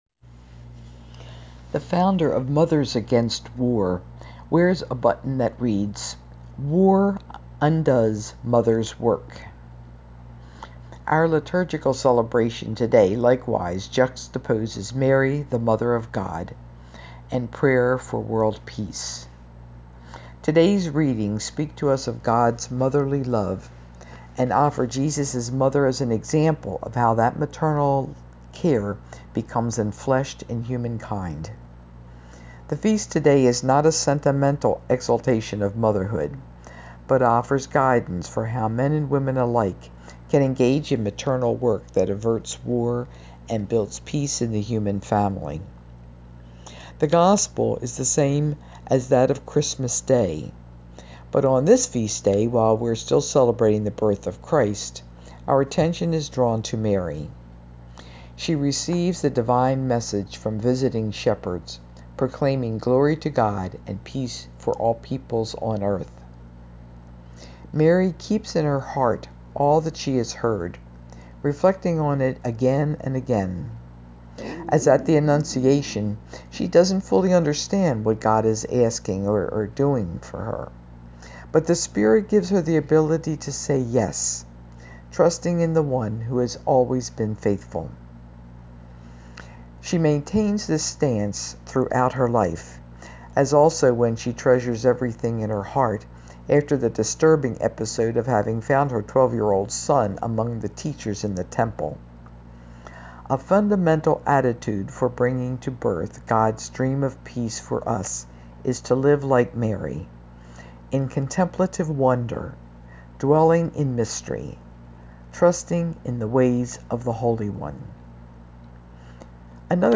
I have recorded my reflection on today's readings..